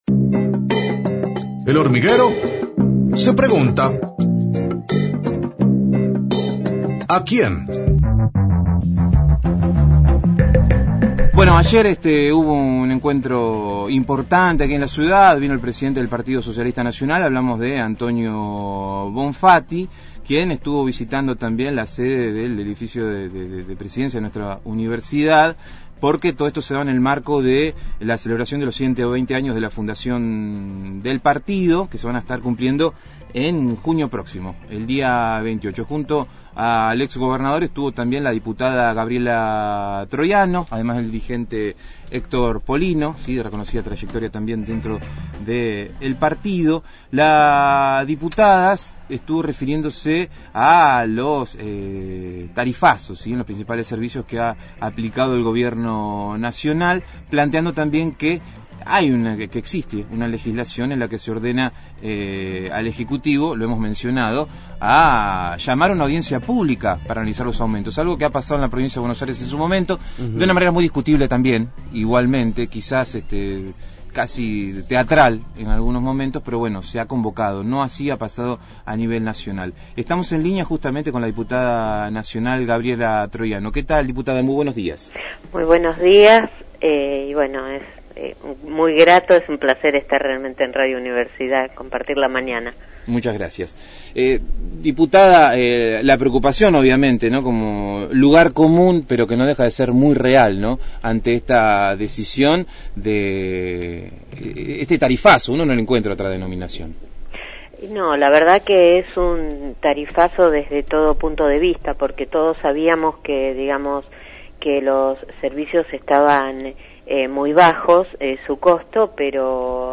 Entrevista a Gabriela Troiano, diputada nacional.